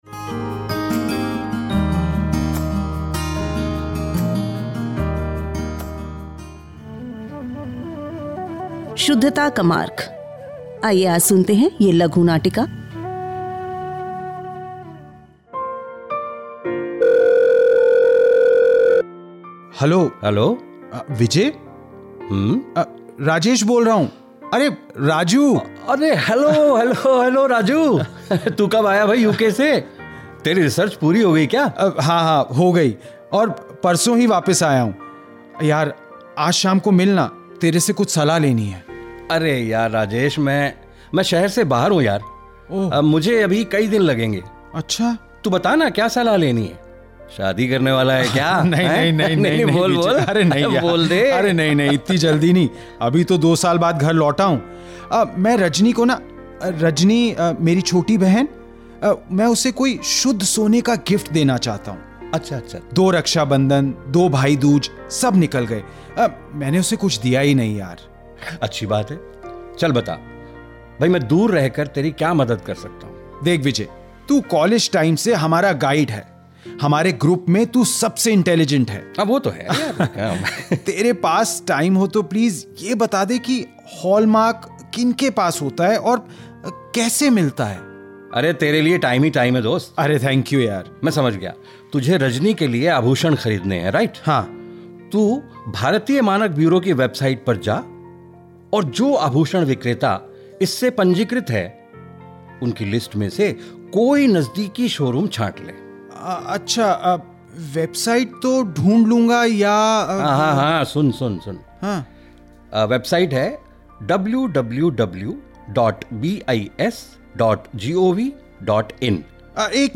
Skit on promotion of Hallmark.